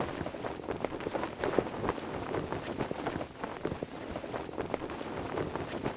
target_wind_float_clothloop.wav